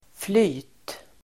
Uttal: [fly:t]